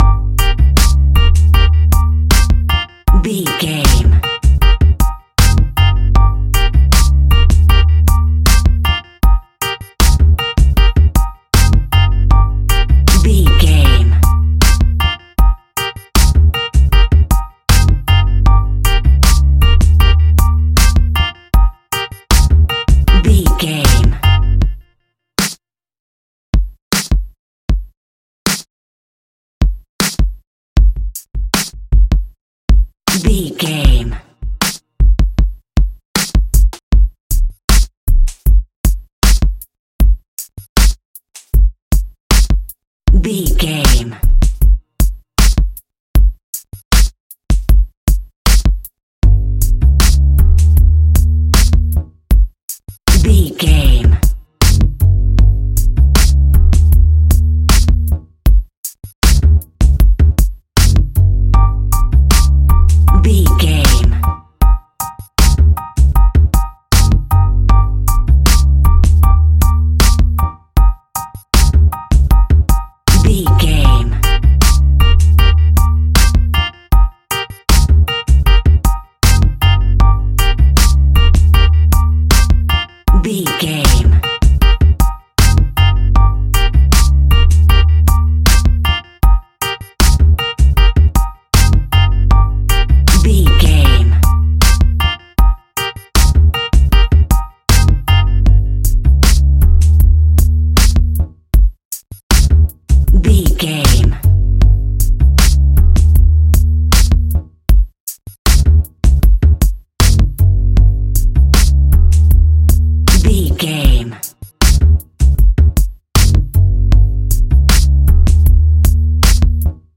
Aeolian/Minor
hip hop
hip hop instrumentals
funky
groovy
east coast hip hop
electronic drums
synth lead
synth bass